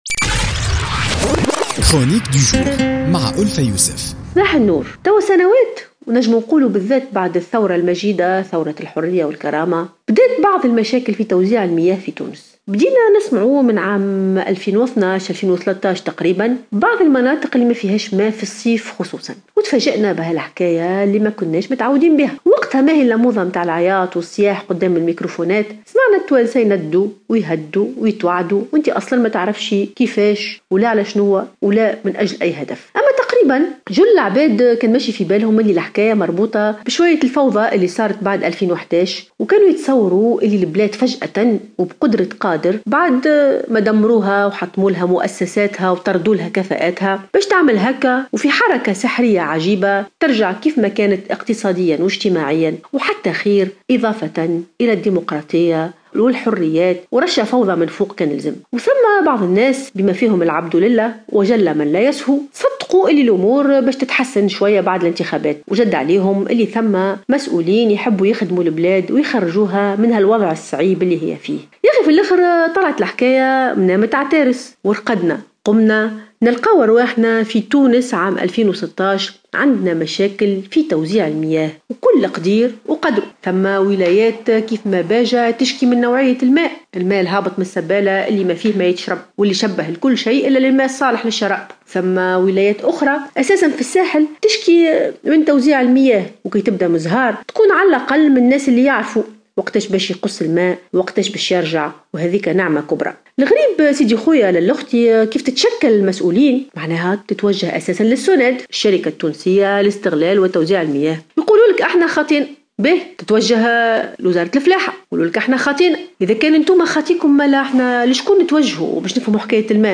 تساءلت الكاتبة ألفة يوسف في افتتاحيتها اليوم الأربعاء بـ "الجوهرة أف أم" عن سبب الظهور المفاجئ لمشاكل المياه في تونس في الآونة الأخيرة أساسا وبعد الثورة التونسية عموما.